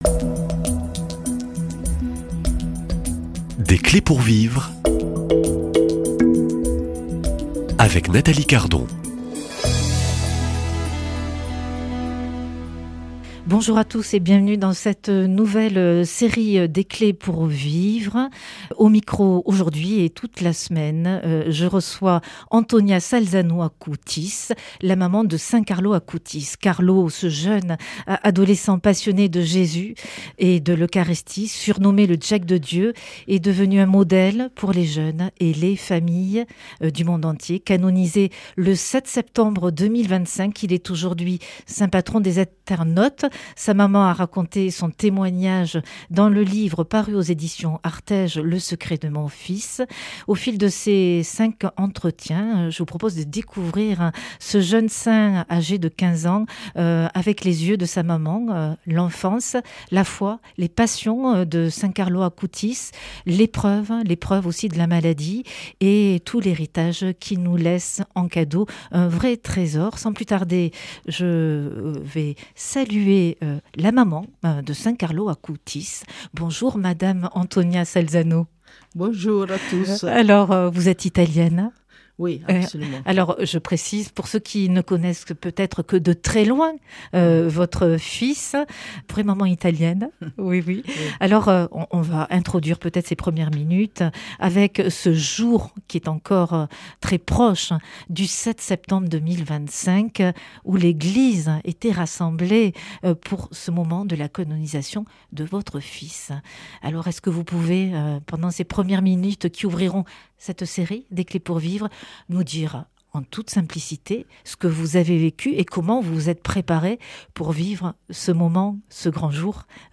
Pour cette série de cinq entretiens